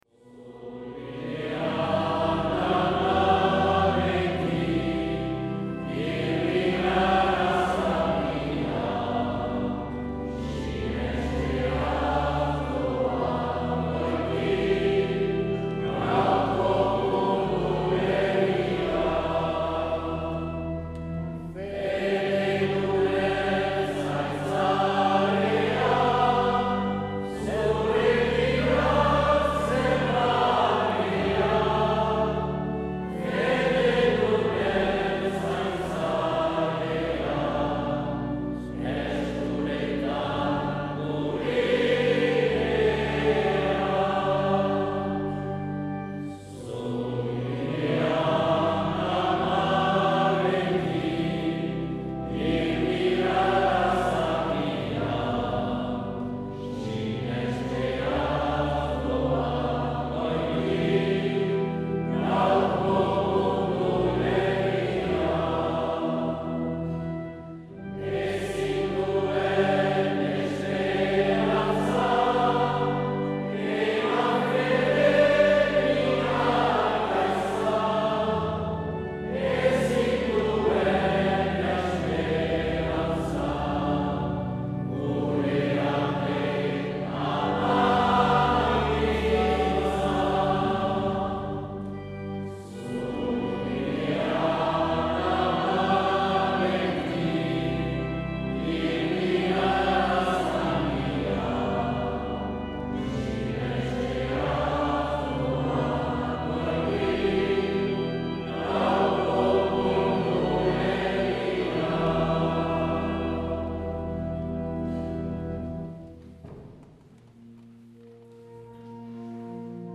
Orriako beila 2024 Bezperak